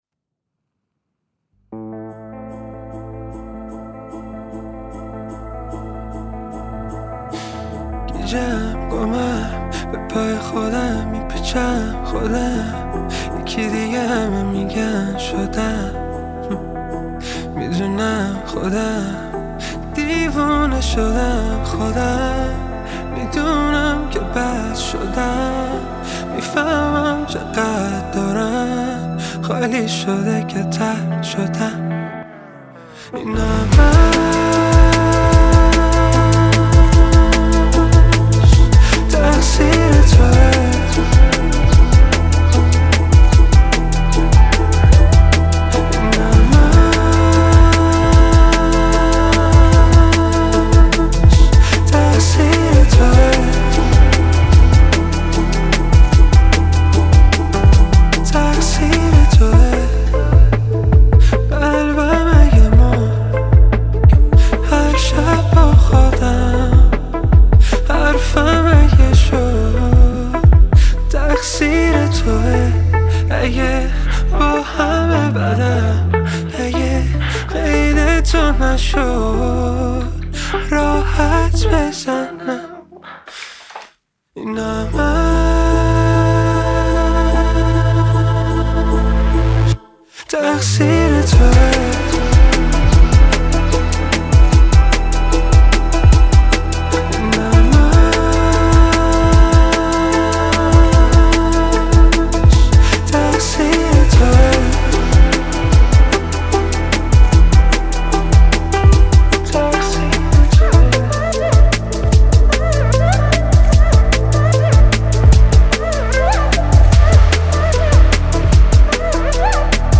موسیقی پاپ ایران
ملودی‌های روان و تنظیم‌های مدرن